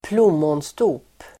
Ladda ner uttalet
Uttal: [²pl'om:ånsto:p]